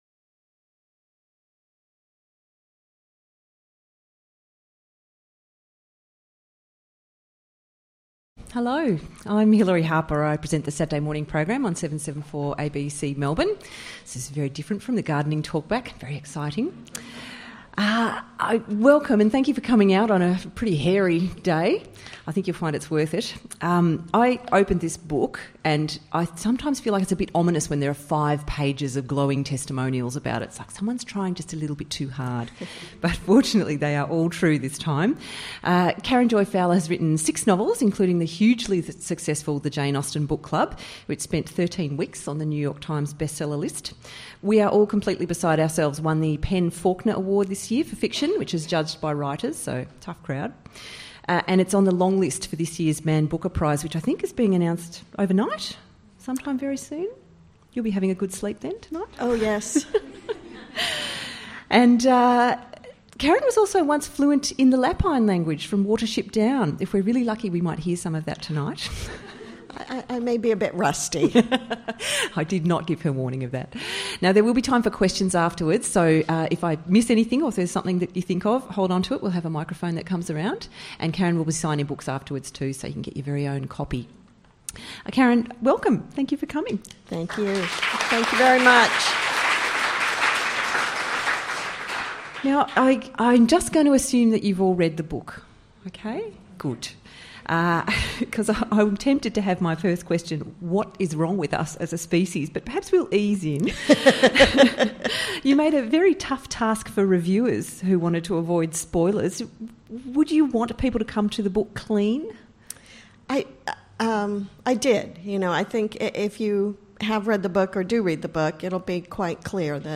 Meet this wise, warm and much-loved writer – and let her be your guide through the invented worlds of her novels, and the ideas within them. In conversation
Presented in partnership with Brisbane Writers Festival.